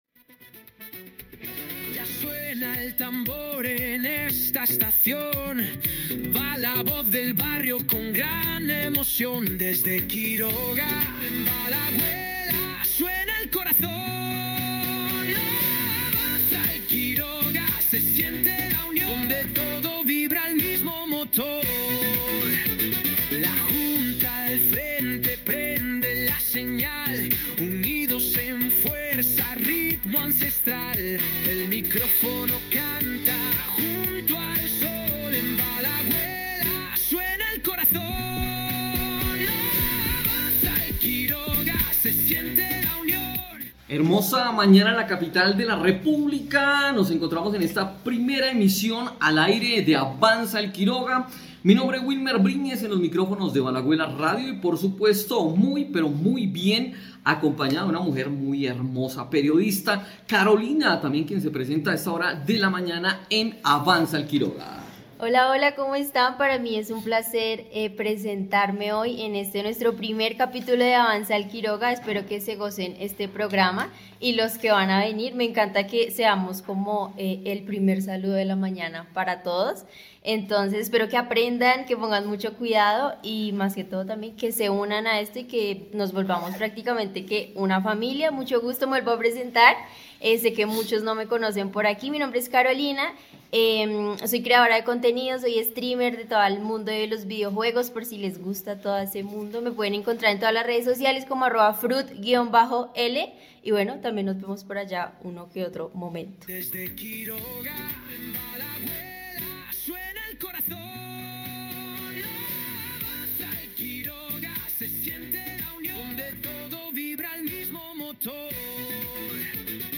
Escucha este episodio con voces comunitarias dedicado a la historia del barrio el Quiroga.